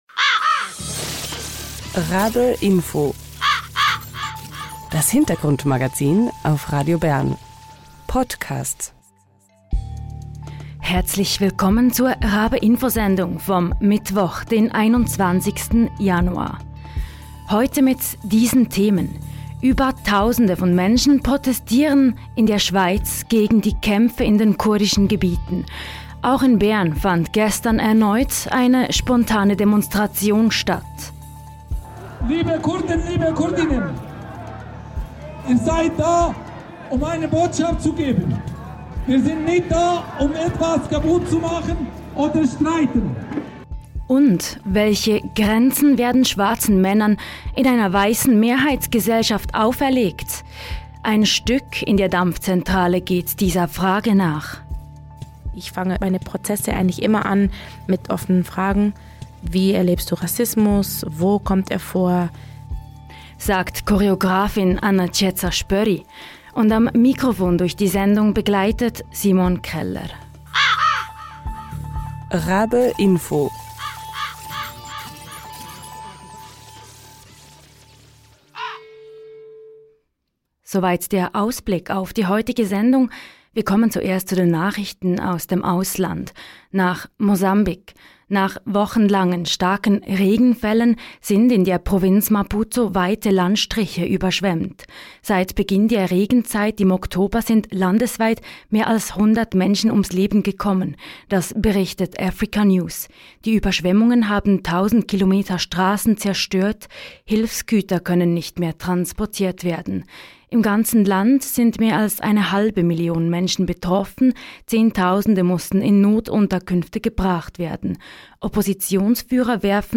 RaBe-Info berichtet.